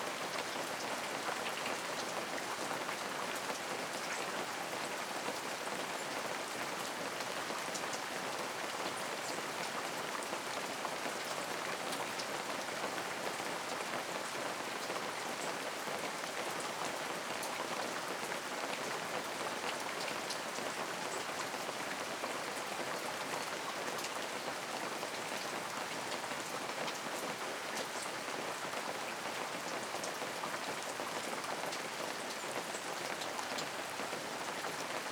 Light Rain.wav